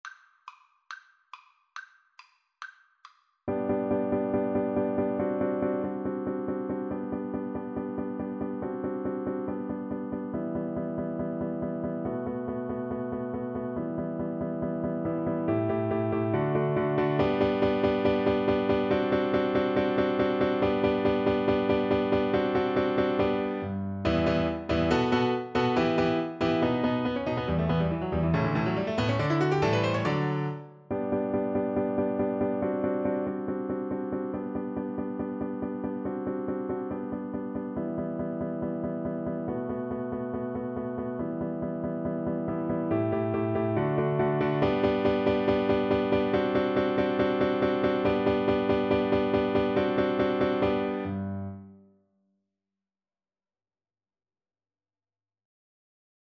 ~ = 140 Allegro vivace (View more music marked Allegro)
Classical (View more Classical Violin Music)